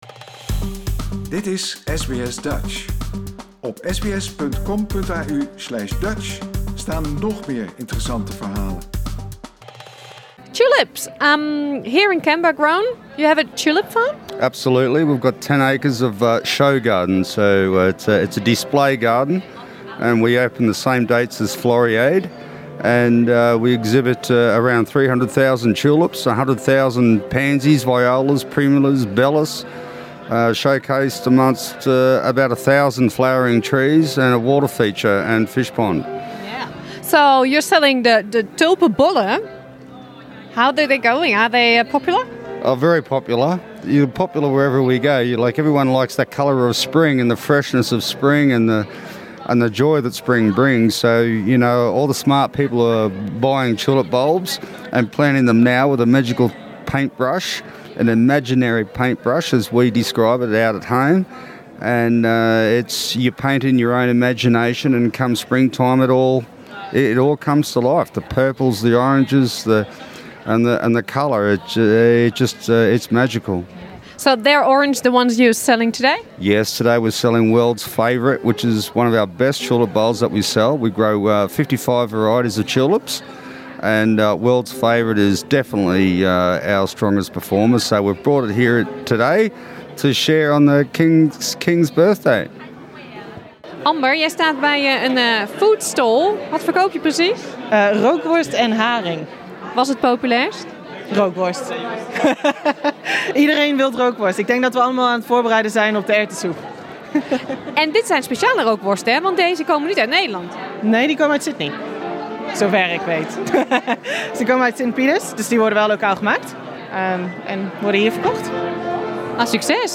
SBS Dutch nam op Koningsdag een kijkje op het Dutch Fest in Canberra.